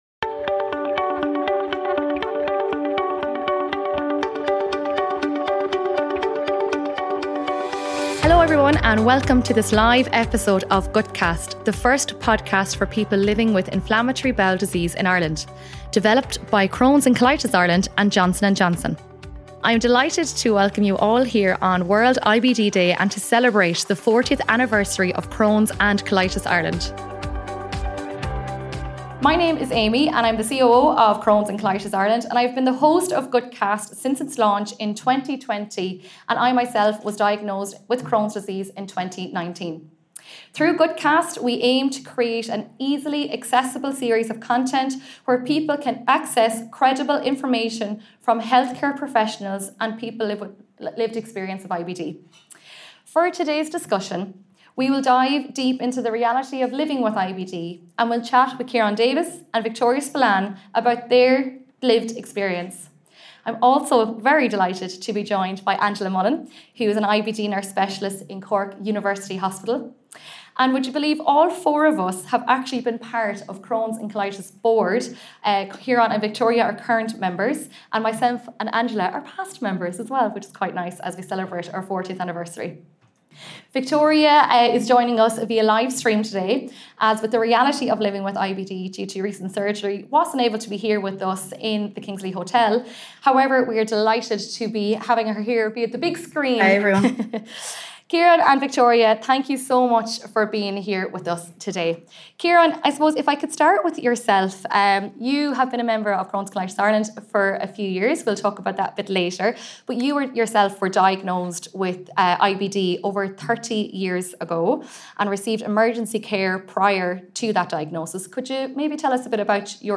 The episode was recorded at CCI’s ‘It Takes Guts’ conference, which also marked the 40th anniversary of the charity.